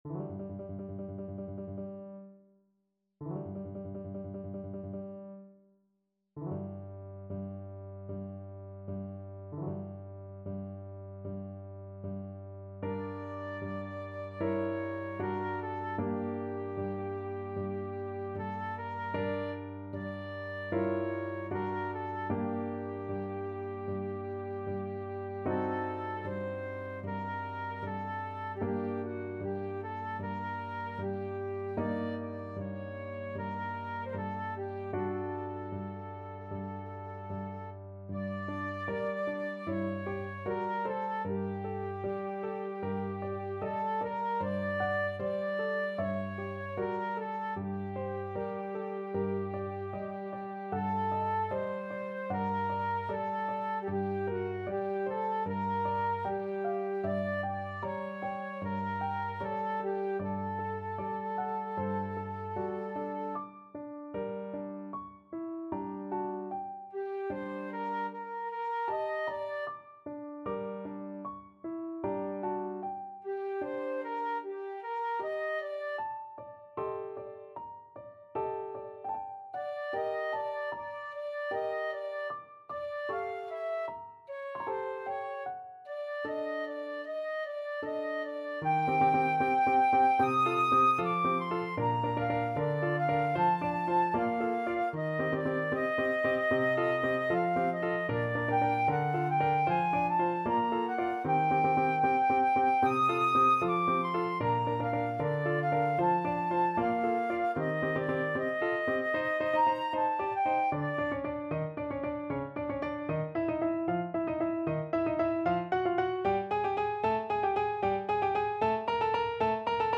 Flute
= 76 Moderato in modo di marcia funebre
4/4 (View more 4/4 Music)
G minor (Sounding Pitch) (View more G minor Music for Flute )
Classical (View more Classical Flute Music)